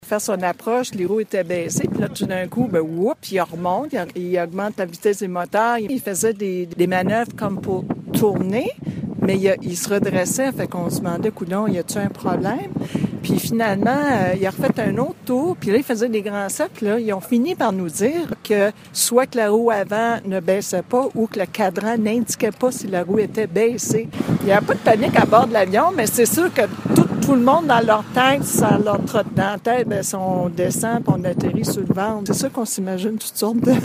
Une passagère explique tout de même que personne n’a paniqué durant l’événement.